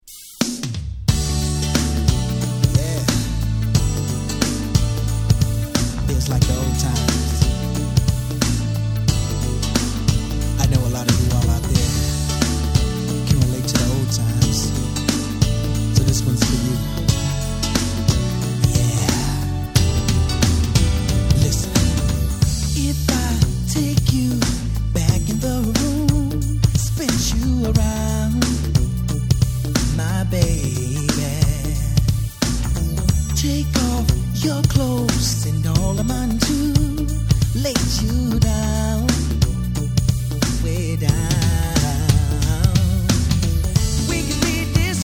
92' Smash Hit R&B !!
この時期の彼の楽曲にしては珍しく、本作はイケイケなNew Jackナンバーではなく、まったりとした甘いMidナンバー。